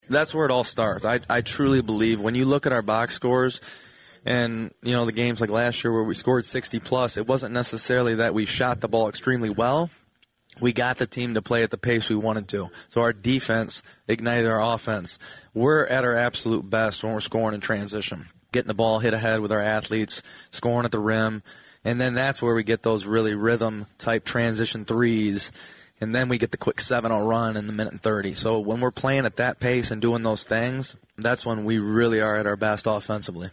We are about two weeks away from tipping-off the high school boys basketball season. 96.5 The Cave and Lenawee TV aired their annual Coaches Preview Show live from Skytech Sports Bar on Main Street in Adrian on Sunday.